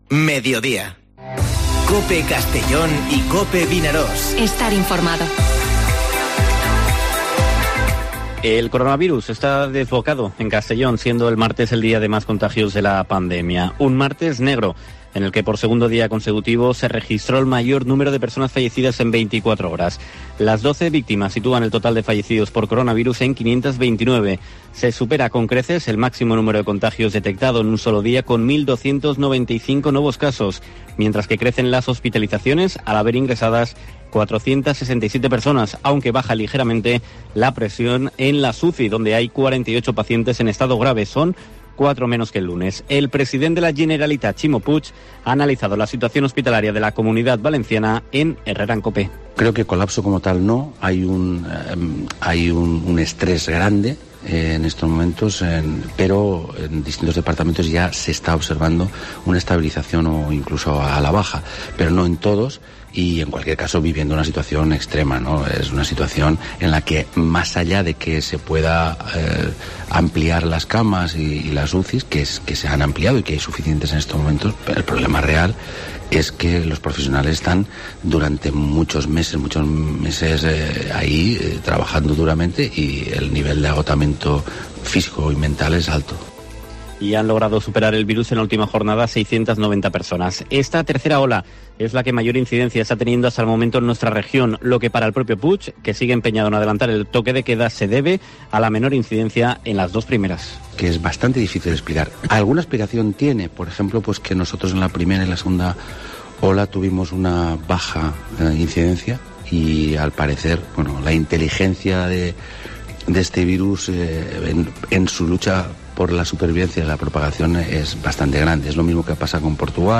Informativo Mediodía COPE en la provincia de Castellón (28/01/2021)